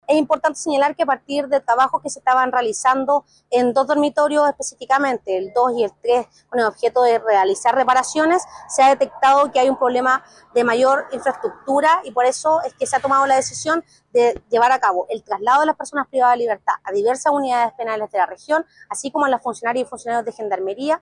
Así lo afirmó, en entrevista con La Radio, la seremi de Justicia y Derechos Humanos de la región de Valparaíso, Paula Gutiérrez, quien además detalló lo ocurrido con el recinto.